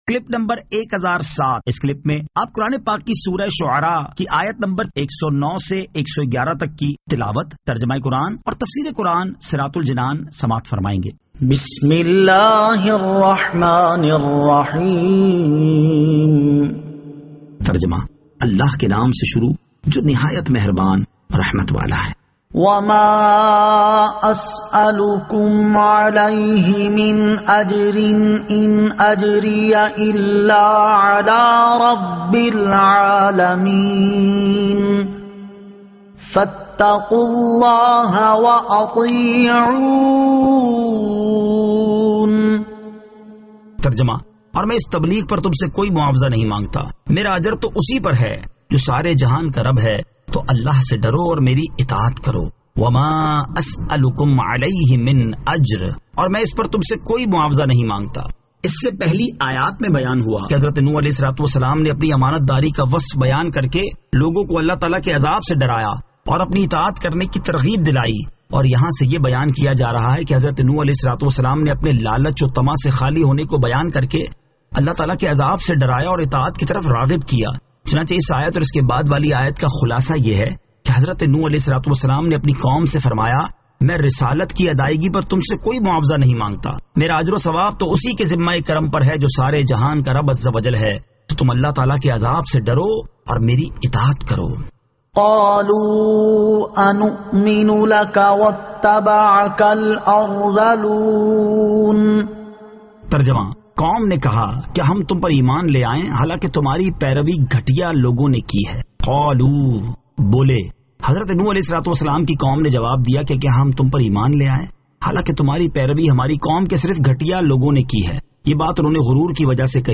Surah Ash-Shu'ara 109 To 111 Tilawat , Tarjama , Tafseer